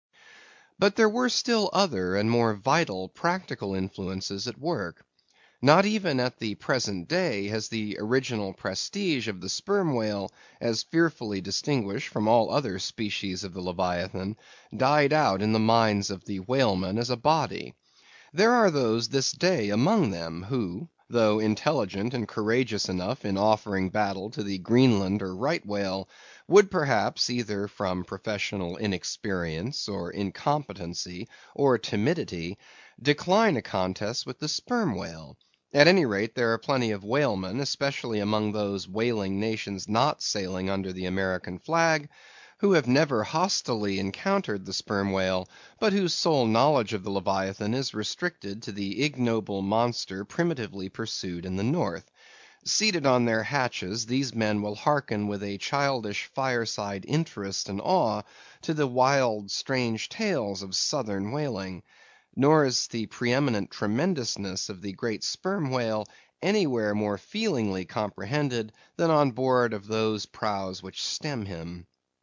英语听书《白鲸记》第437期 听力文件下载—在线英语听力室